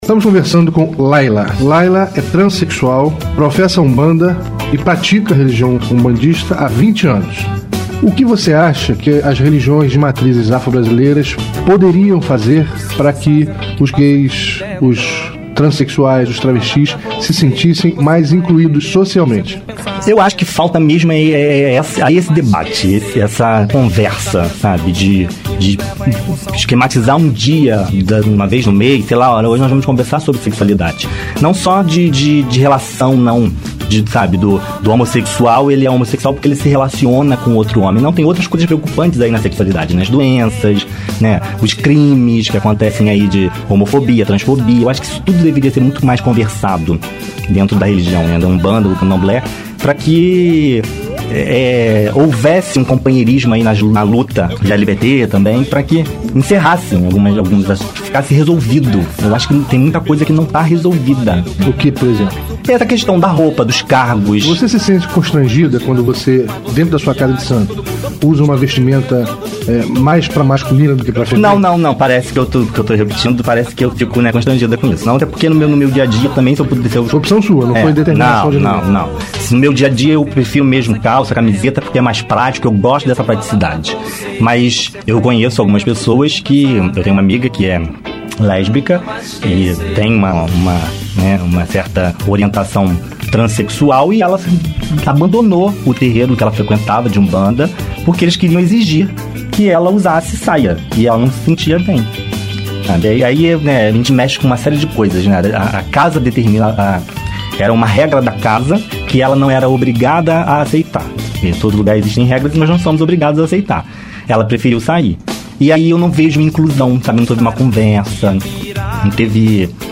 Entrevistas e Debates